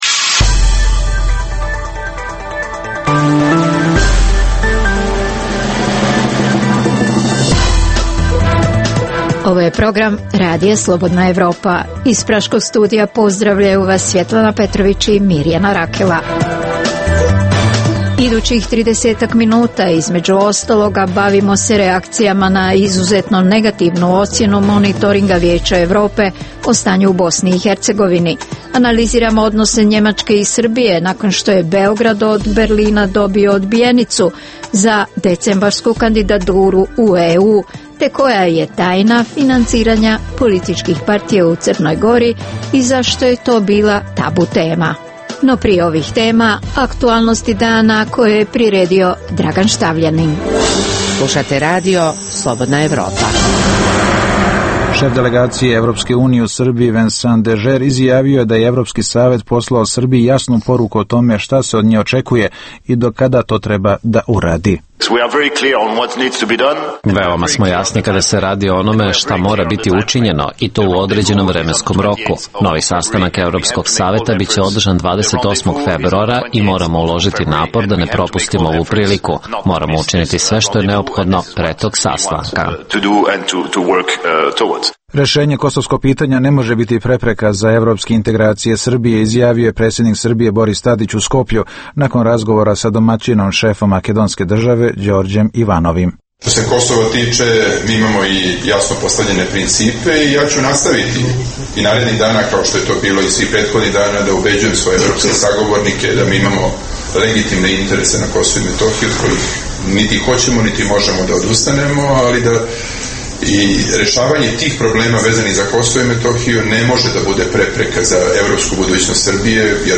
U emisiji možete čuti: - U temi sedmice govorimo o načinima financiranja udruženja žrtava rata u BiH, kako dobijaju i pravdaju budžetski novac, te koliko novca koji dobijaju nevladine organizacije stiže do onih kojima je i potreban.